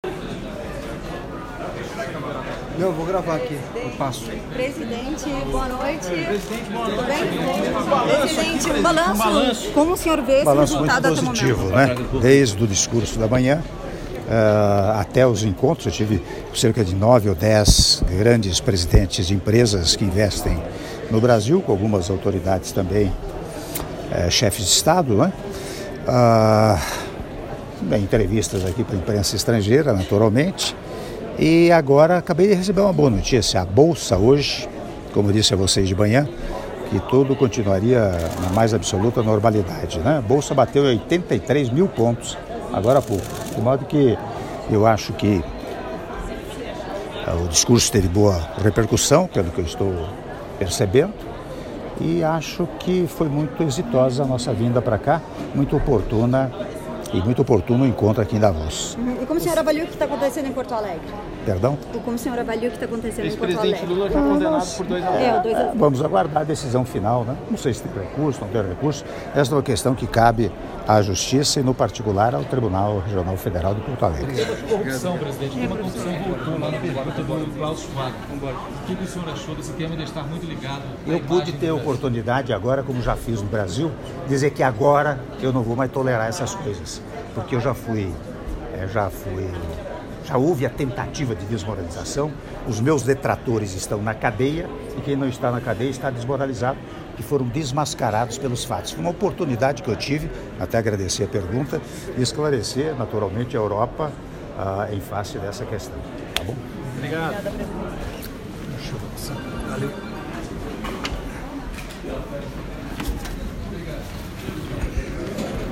Áudio da entrevista coletiva concedida pelo Presidente da República, Michel Temer, na saída do Hotel Seehof para o Hotel Derby - Davos/Suíça (01min55s)